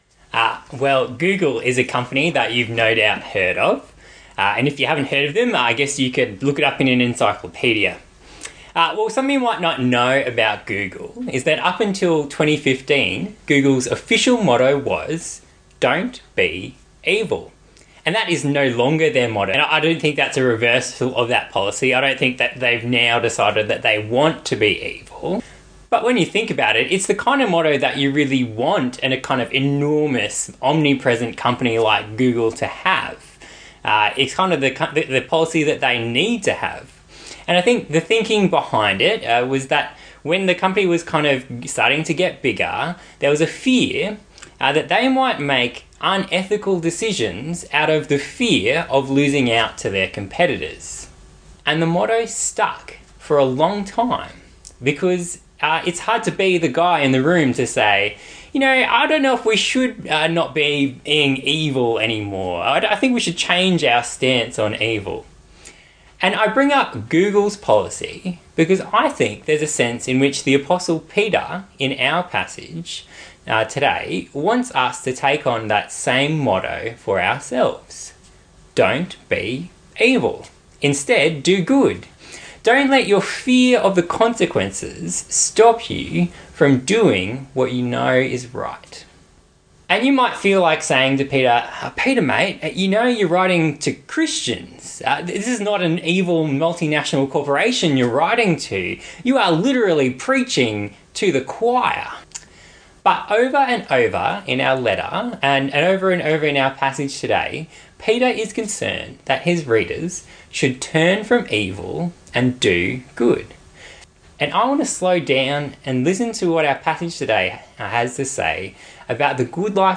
1-Peter-Sermon-3_8_22-Final.output.m4a